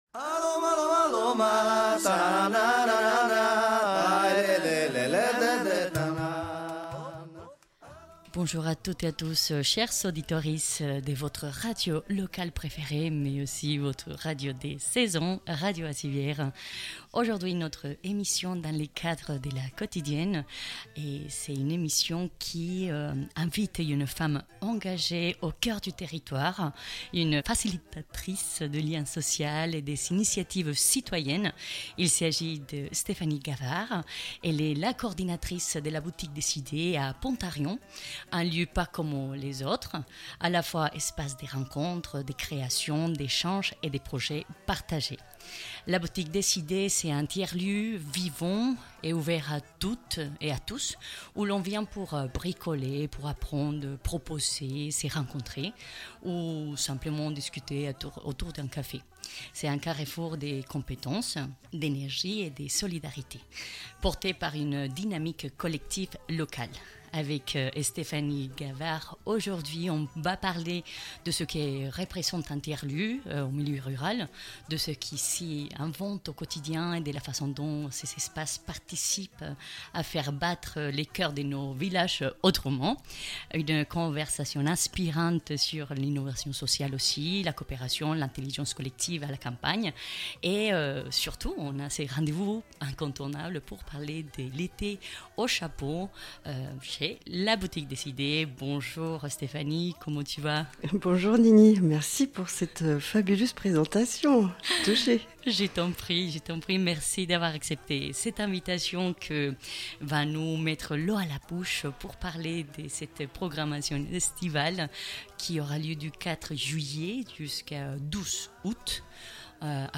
pour une interview exclusive par téléphone.